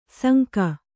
♪ sanka